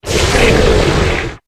Grito de Sandaconda.ogg
) Categoría:Gritos de Pokémon de la octava generación Categoría:Sandaconda No puedes sobrescribir este archivo.
Grito_de_Sandaconda.ogg